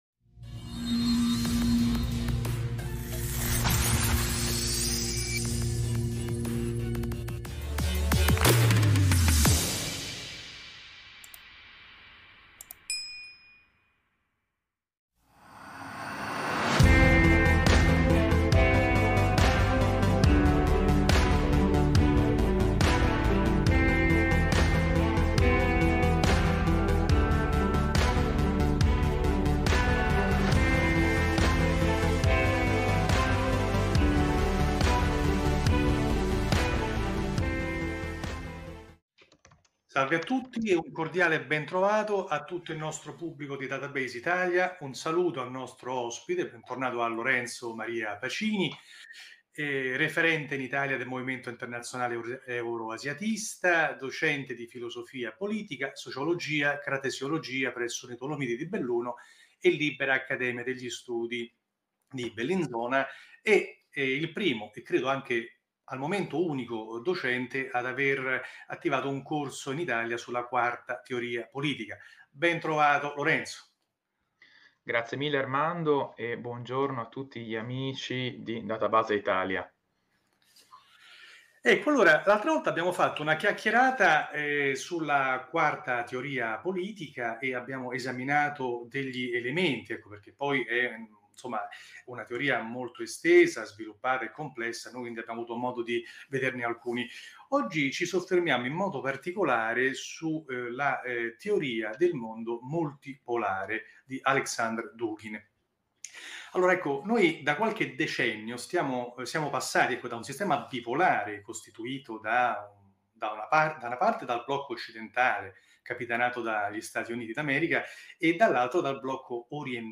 KATHECHON - Il Nuovo Ordine Multipolare | Intervista